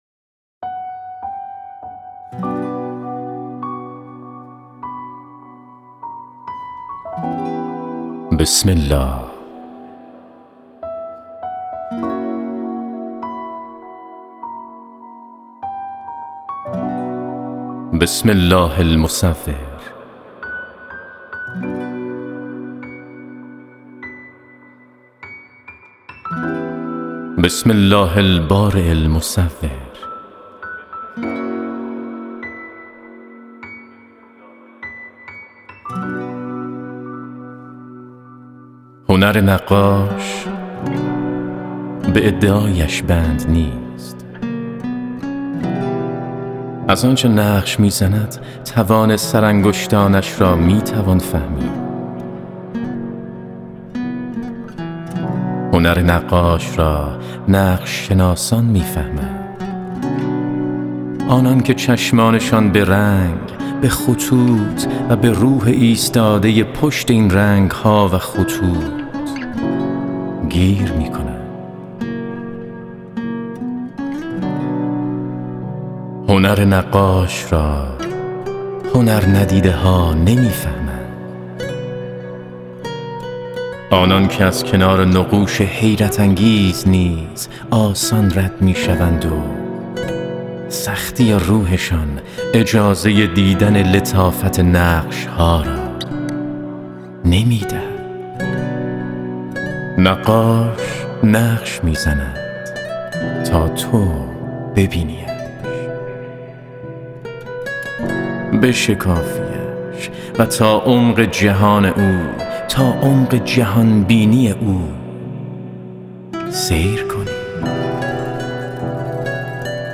دکلمه